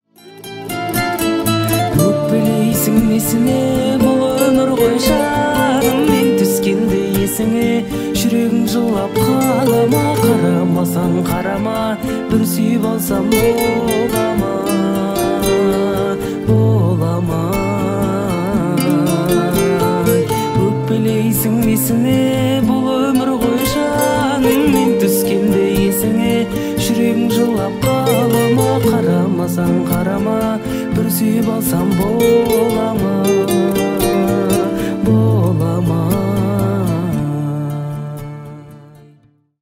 спокойные